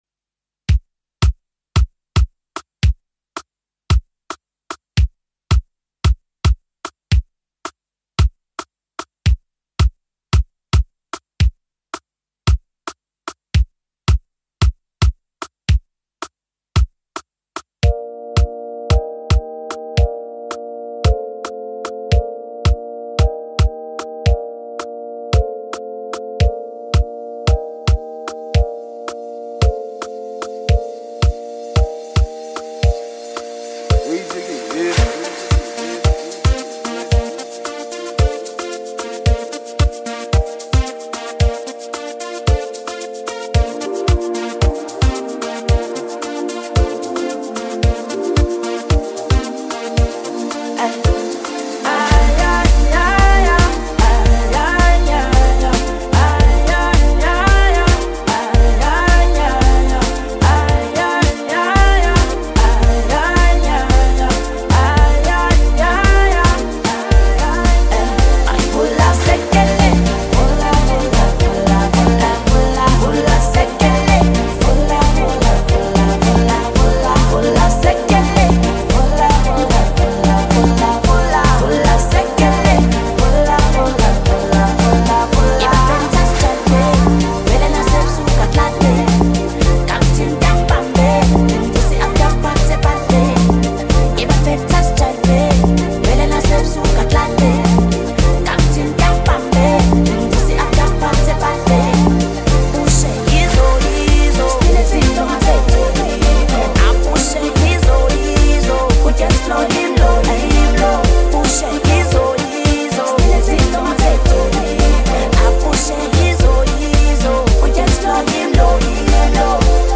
heartwarming recording